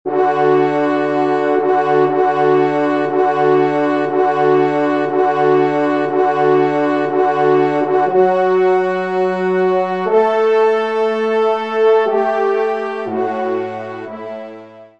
Genre :  Musique Religieuse pour Trois Trompes ou Cors
Pupitre 4° Trompe